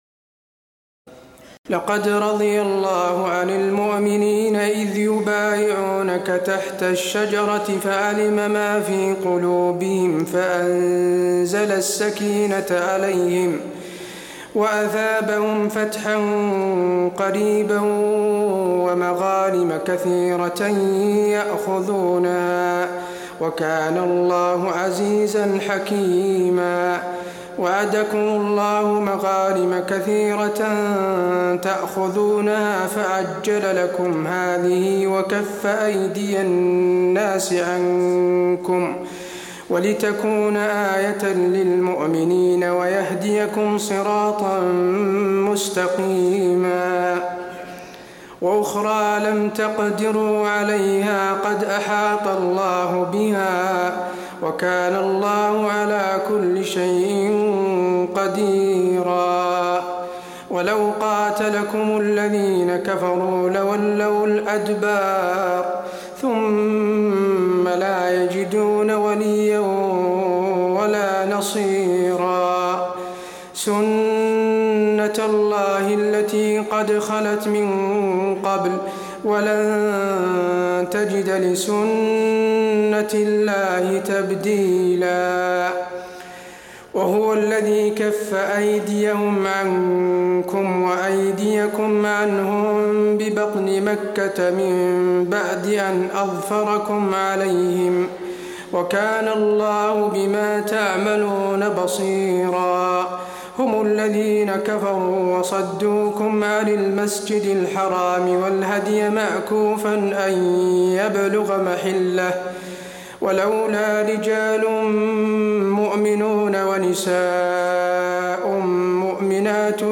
تراويح ليلة 25 رمضان 1426هـ من سور الفتح (18-29) الحجرات وق Taraweeh 25 st night Ramadan 1426H from Surah Al-Fath and Al-Hujuraat and Qaaf > تراويح الحرم النبوي عام 1426 🕌 > التراويح - تلاوات الحرمين